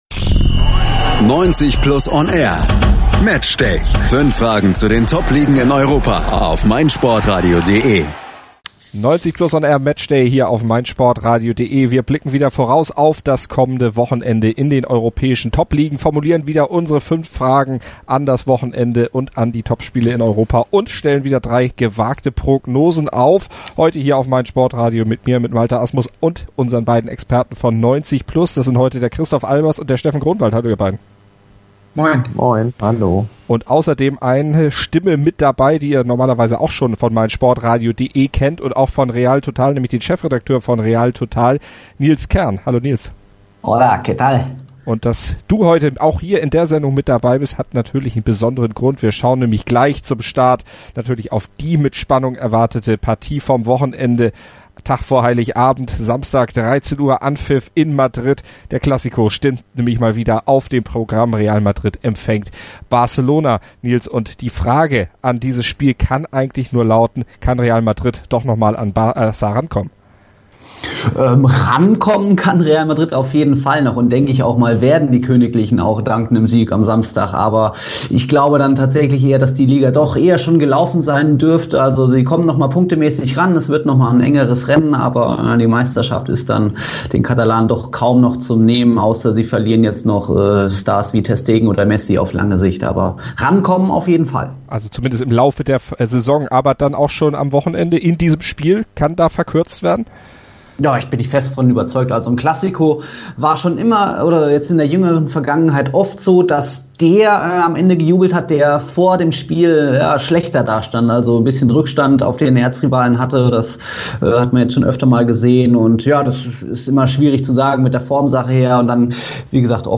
Unsere Viererkette formuliert die 5 Fragen an den Spieltag, stellt drei ziemlich gewagte Prophezeiungen auf und liefert euch die wichtigsten Fakten zu den europäischen Topspielen. Kann Real Madrid doch noch mal an Barça rankommen?